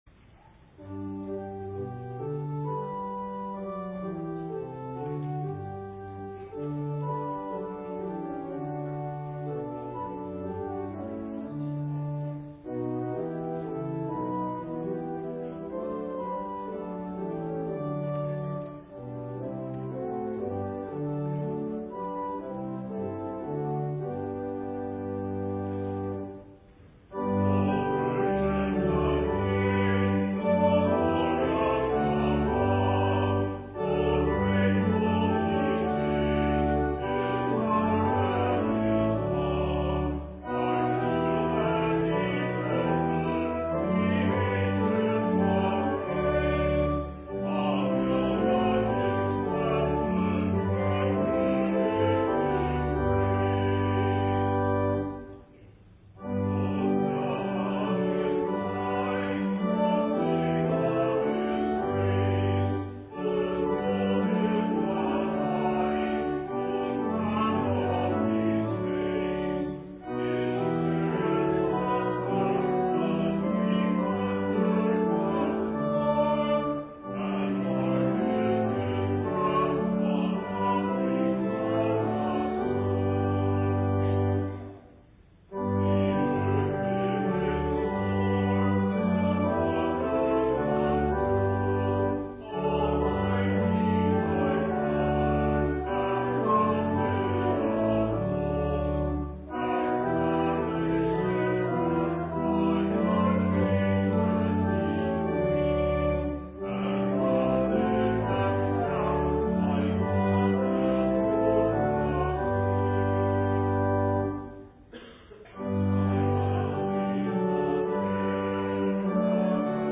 LITURGY: Pg 15 in TLH – Divine Service with Holy Communion